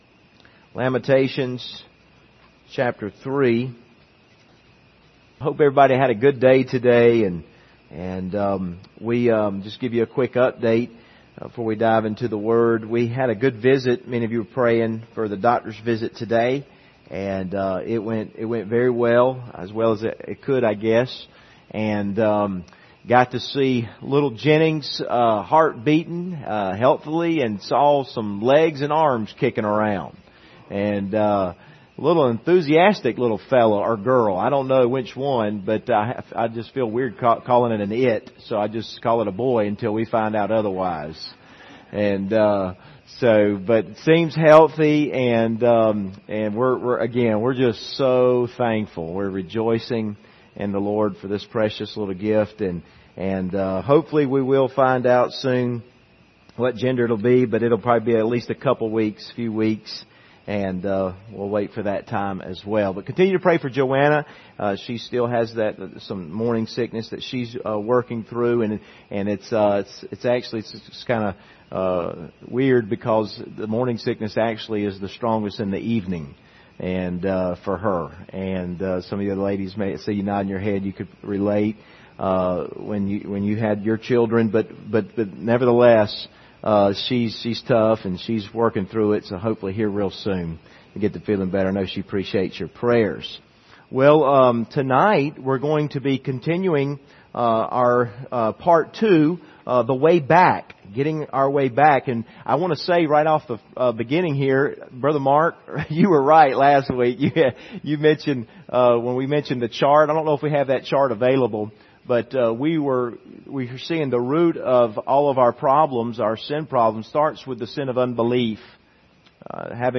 Service Type: Wednesday Evening Topics: God's faithfulness